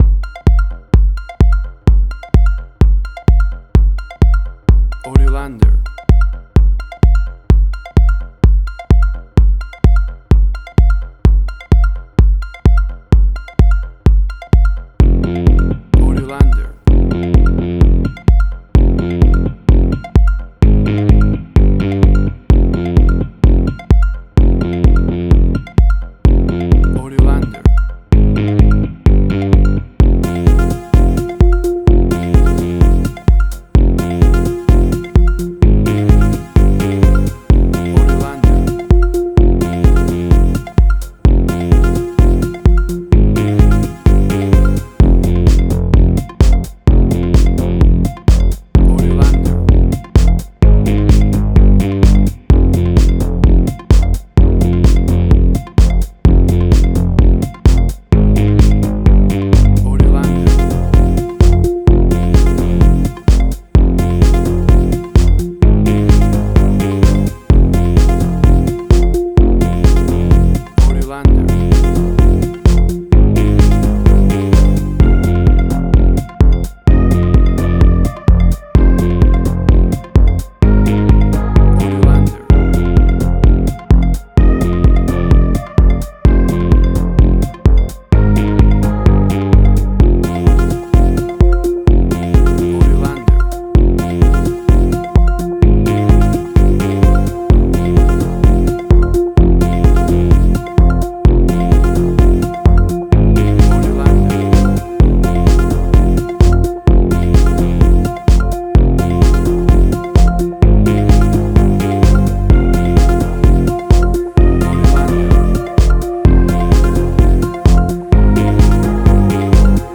House.
Tempo (BPM): 128